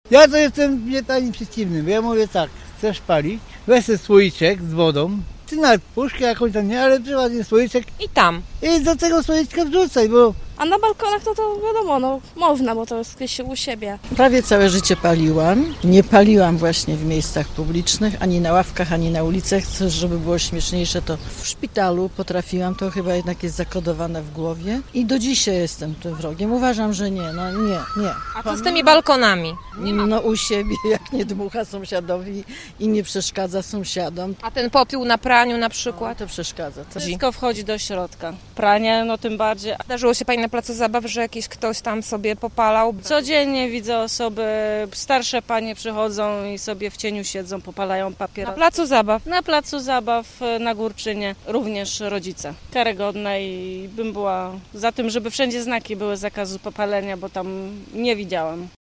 A co o palących na balkonach i nie tylko sąsiadach mówią sami gorzowianie?